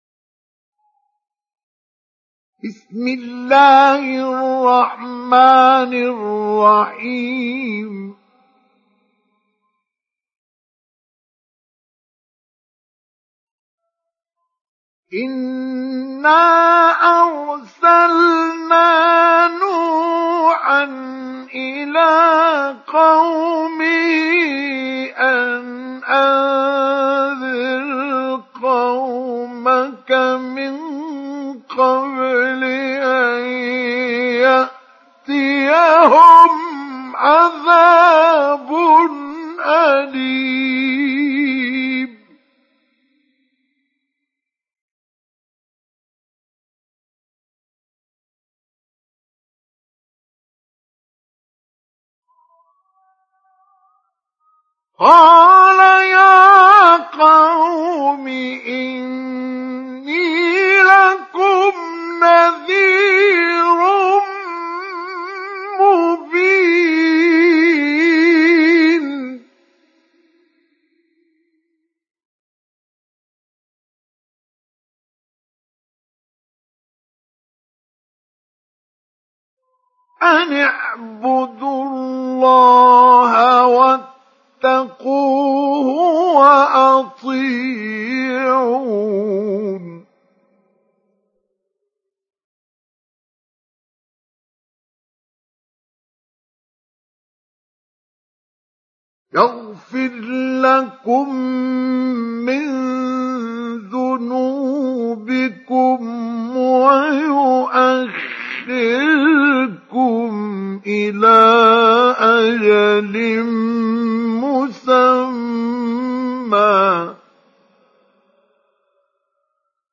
سُورَةُ نُوحٍ بصوت الشيخ مصطفى اسماعيل